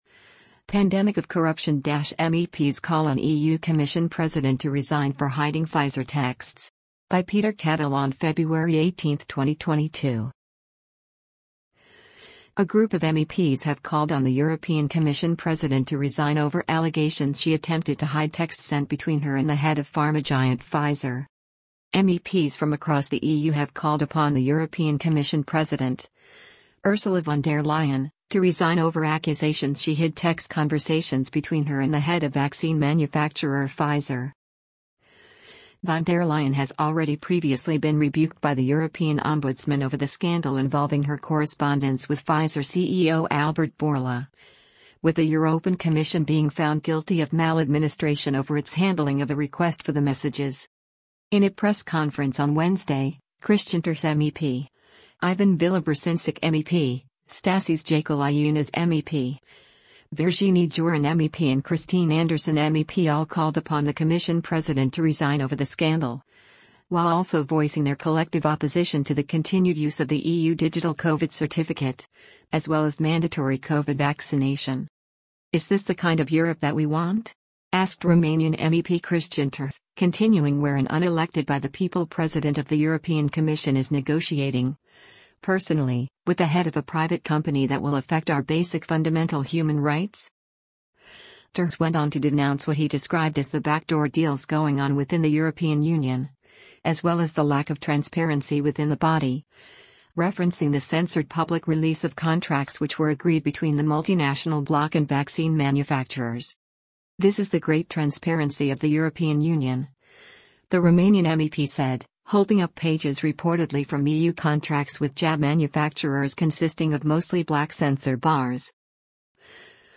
In a press conference on Wednesday, Cristian Terheș MEP, Ivan Vilibor Sinčić MEP, Stasys Jakeliūnas MEP, Virginie Joron MEP and Christine Anderson MEP all called upon the Commission president to resign over the scandal, while also voicing their collective opposition to the continued use of the EU Digital Covid Certificate, as well as mandatory COVID vaccination.